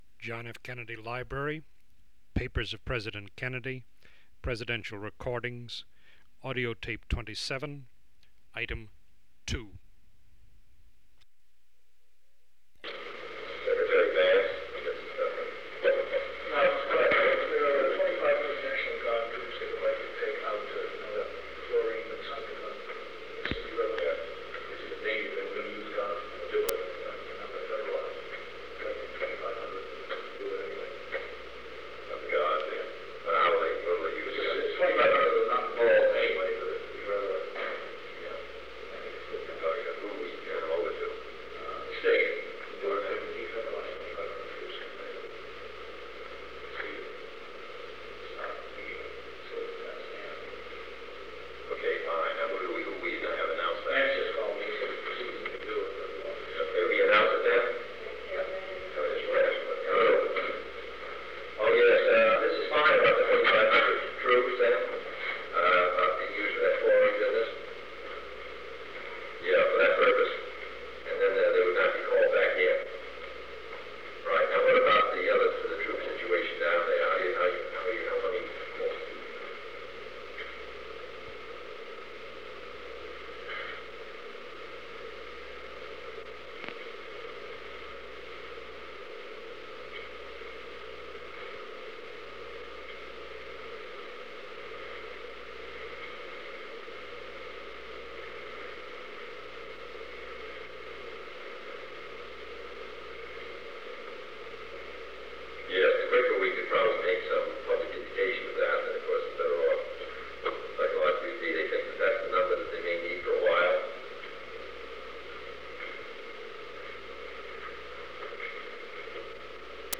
Conversation with Kenneth O’Donnell and Cyrus Vance
Secret White House Tapes | John F. Kennedy Presidency Conversation with Kenneth O’Donnell and Cyrus Vance Rewind 10 seconds Play/Pause Fast-forward 10 seconds 0:00 Download audio Previous Meetings: Tape 121/A57.